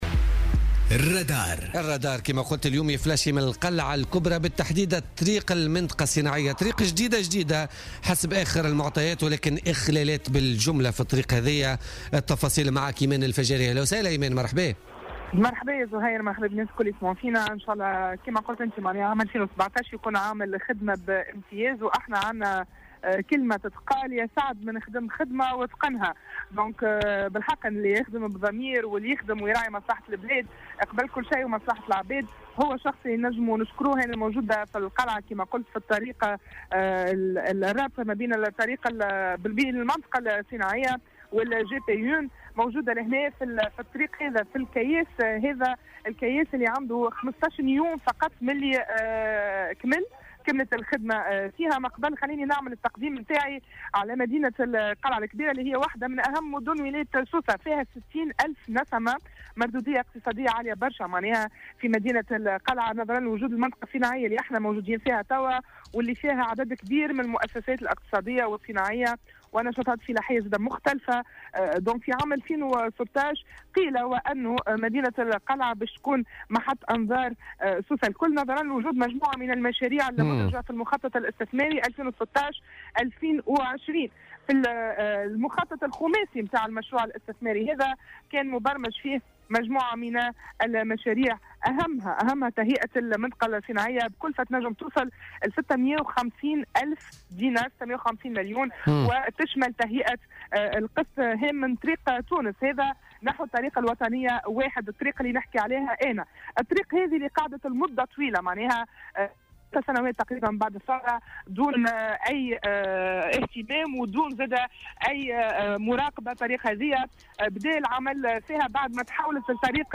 تحوّل فريق "الرادار" اليوم الاثنين إلى منطقة القلعة الكبرى من ولاية سوسة لمعاينة حالة الطريق الجديدة التي تم الانتهاء من أشغال تهيئتها منذ 15 يوما فقط.